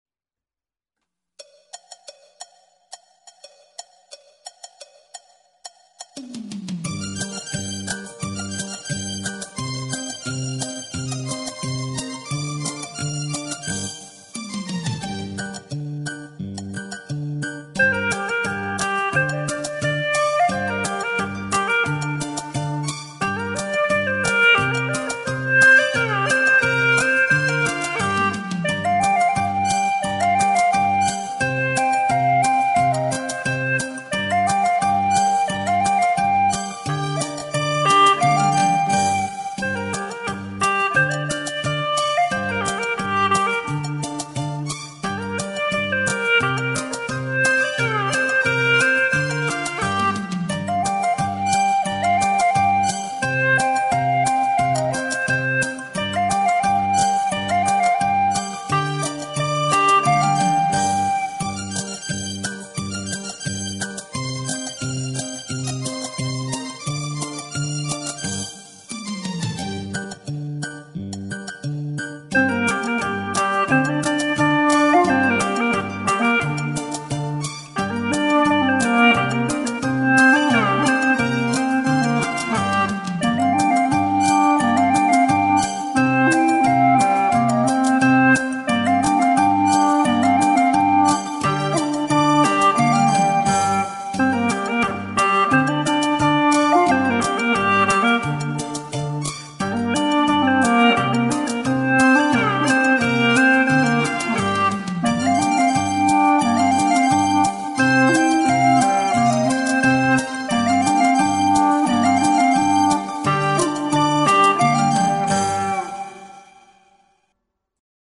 调式 : 降B 曲类 : 民族